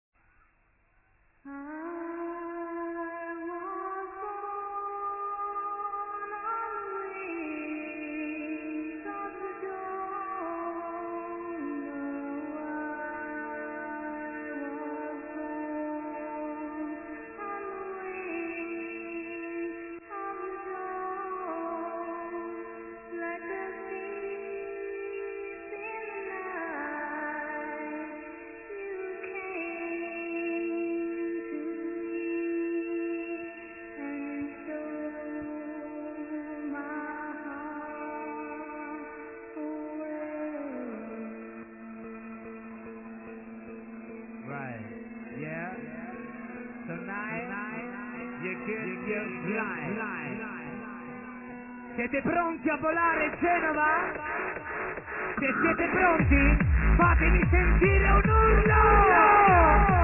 HardStyle ID
Can u id this hardstyle track with good female voice!